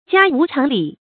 家無常禮 注音： ㄐㄧㄚ ㄨˊ ㄔㄤˊ ㄌㄧˇ 讀音讀法： 意思解釋： 謂家人之間平居不必拘禮。